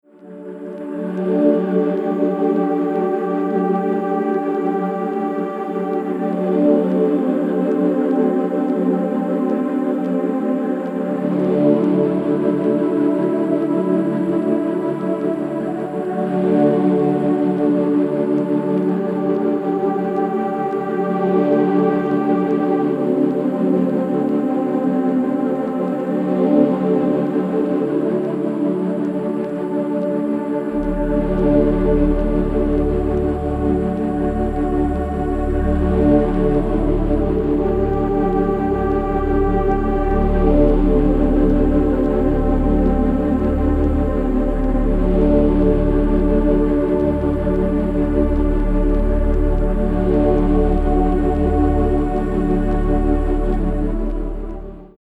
AMBIENT/DOWNTEMPO